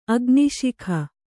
♪ agniśikha